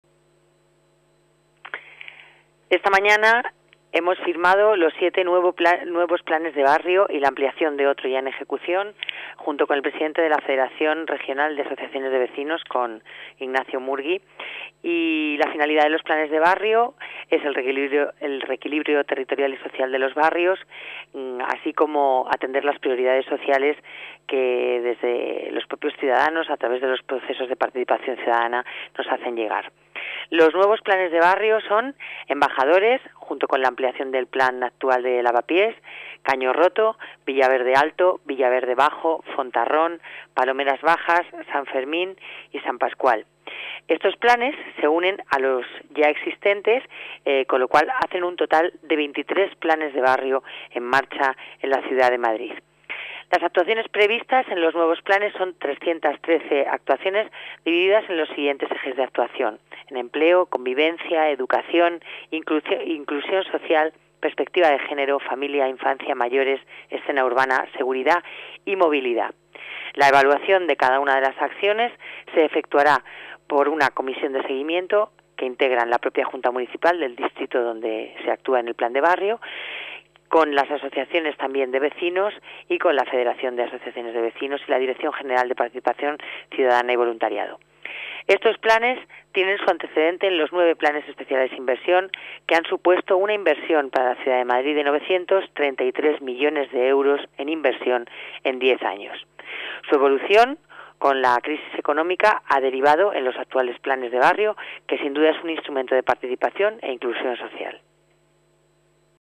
Nueva ventana:Declaraciones delegada Familia, Servicios Sociales y Participación Ciudadana, Lola Navarro: Planes de Barrio